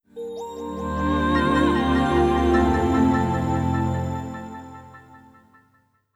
MinderiaOS Delt Startup.wav